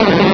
pokeemerald / sound / direct_sound_samples / cries / bellsprout.aif